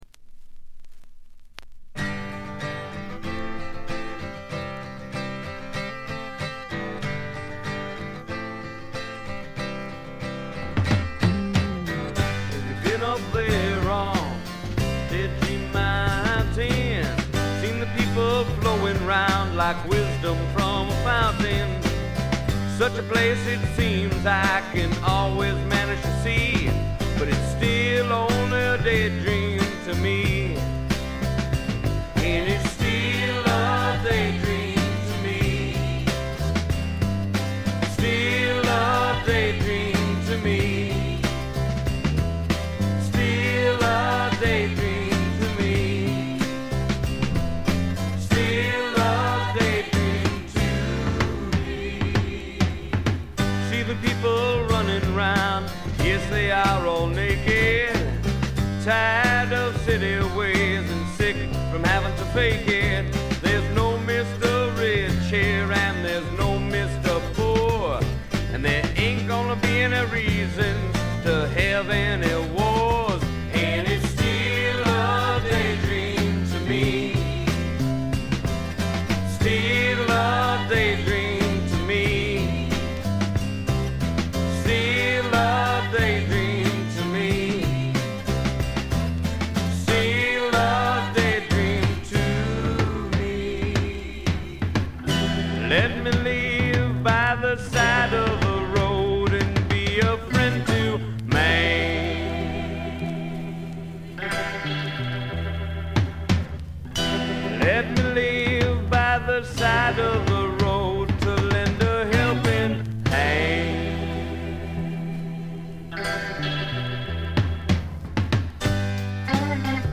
軽微なバックグラウンドノイズ、チリプチ程度。
知られざるスワンプ系シンガー・ソングライターの裏名盤です。
試聴曲は現品からの取り込み音源です。